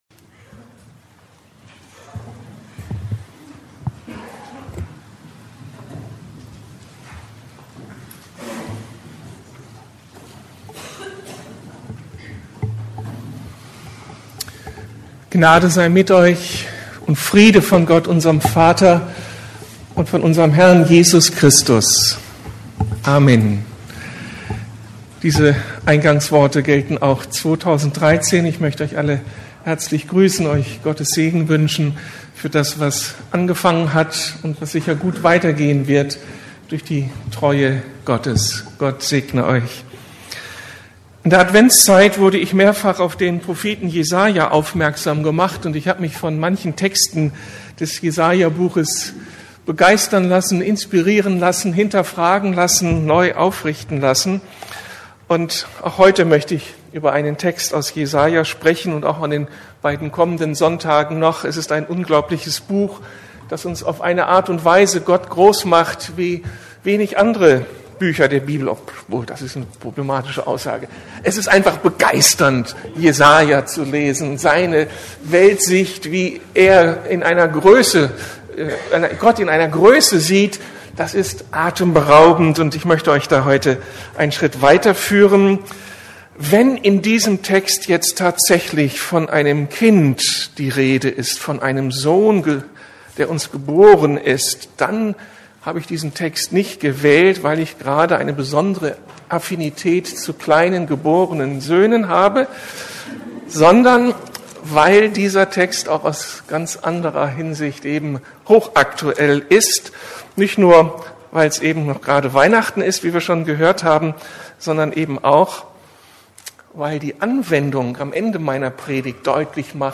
Das Friedenskind ~ Predigten der LUKAS GEMEINDE Podcast